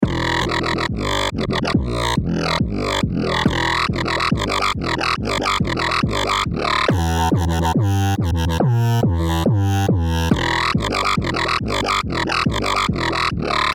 标签： 140 bpm Dubstep Loops Synth Loops 2.31 MB wav Key : Unknown
声道立体声